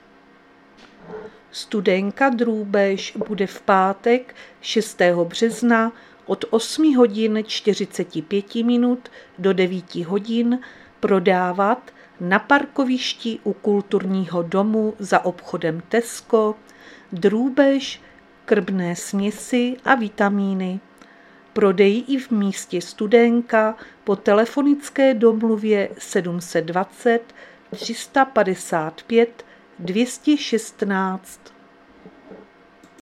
Záznam hlášení místního rozhlasu 2.3.2026
Zařazení: Rozhlas